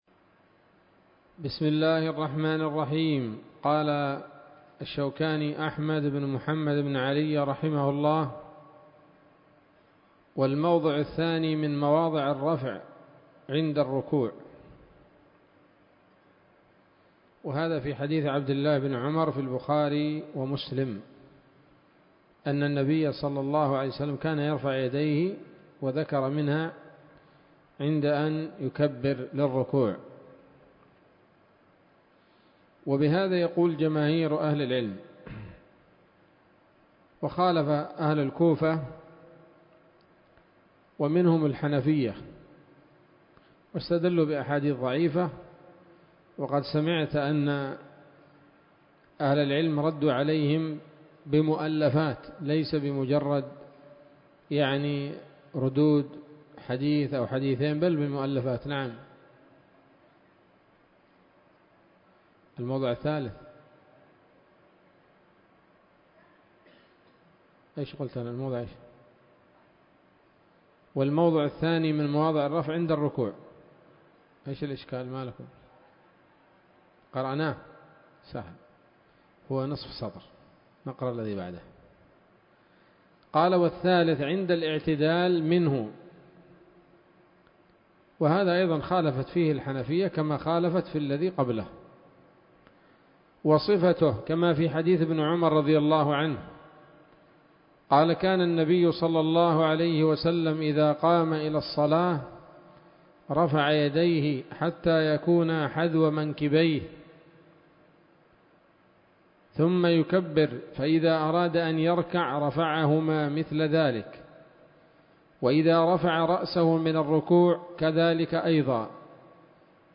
الدرس الخامس عشر من كتاب الصلاة من السموط الذهبية الحاوية للدرر البهية